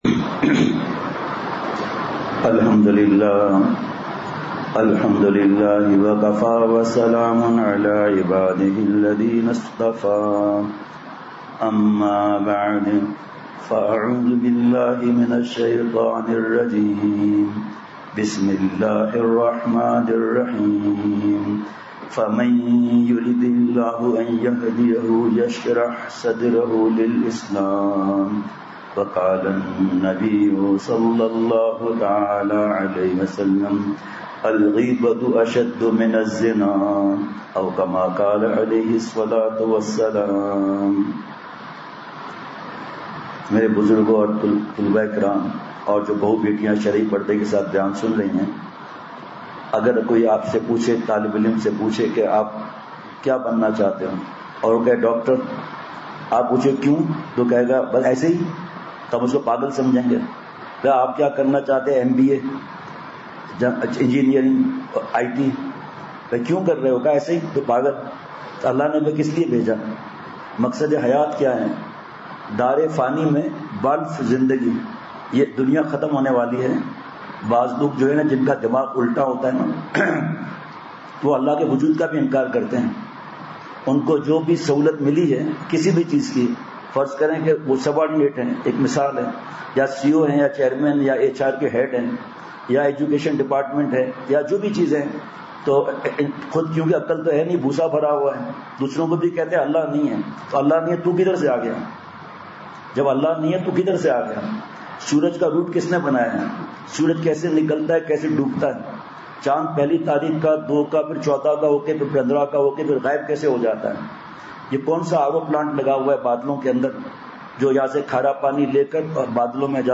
*بمقام: ۔جامع مسجد کریم آغاپائیند کلی خان کوئٹہ* *بعد عشاء بیان*